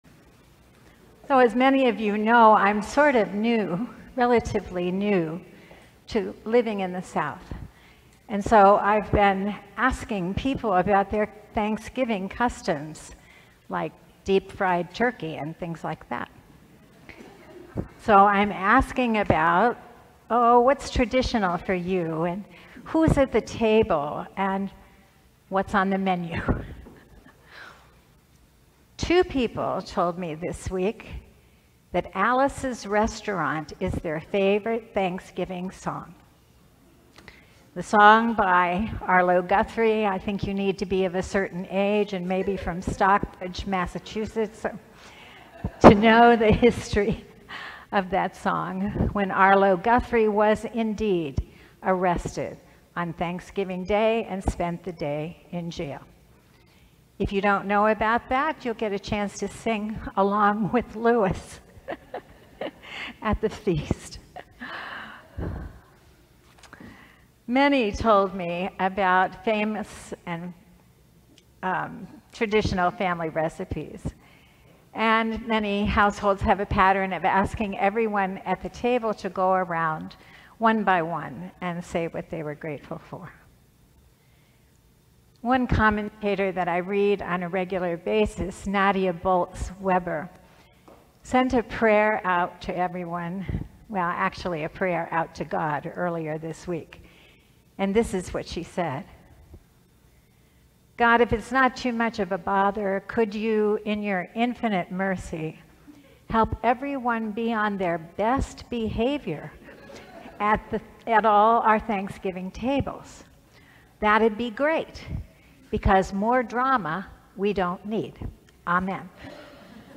Sermon: Thanksgiving Perspectives - St. John's Cathedral
Thanksgiving Day Holy Eucharist